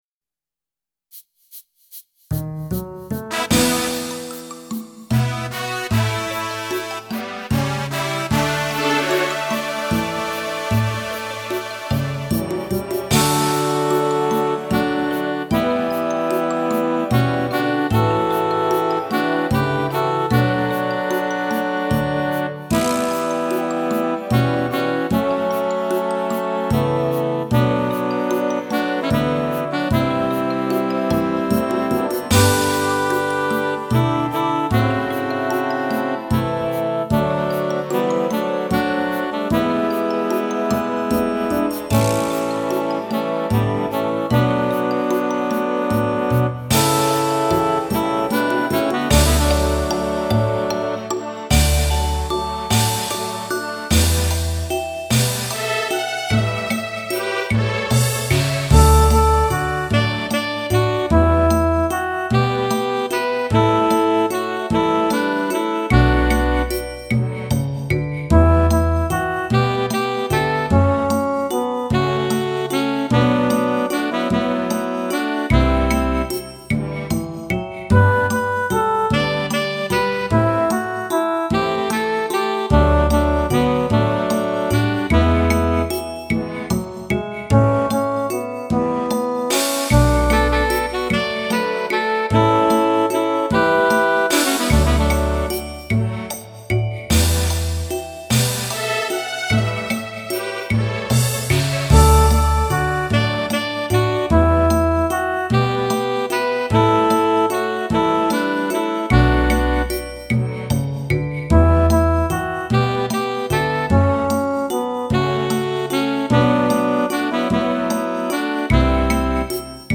沙沙的黑膠片、搭配甜美的歌聲，宛如回到從前。
吉他